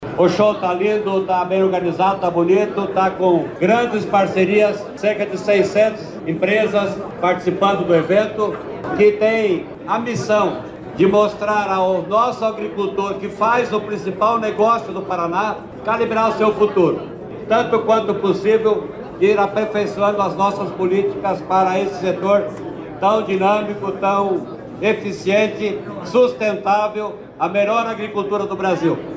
Sonora do secretário da Agricultura e do Abastecimento, Norberto Ortigara, na abertura da 36ª edição do Show Rural Coopavel
NORBERTO ORTIGARA - ABERTURA 36 SHOW RURAL.mp3